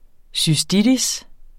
Udtale [ syˈsdidis ]